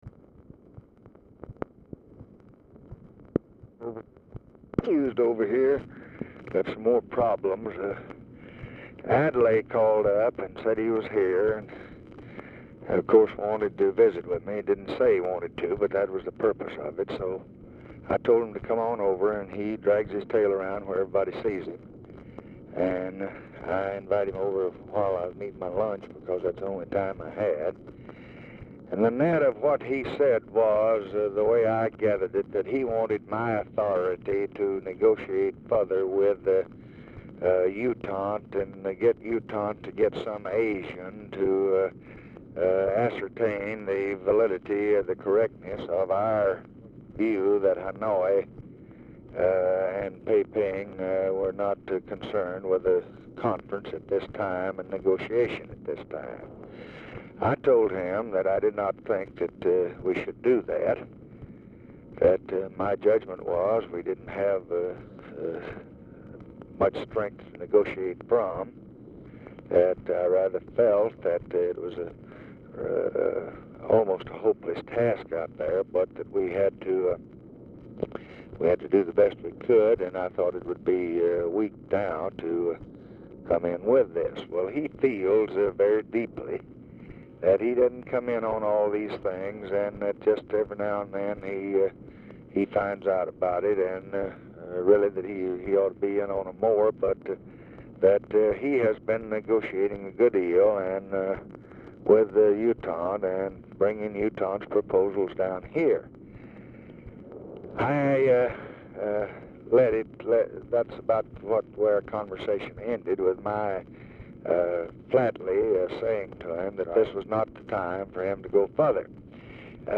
Oval Office or unknown location
RECORDING STARTS AFTER CONVERSATION HAS BEGUN
Telephone conversation
Dictation belt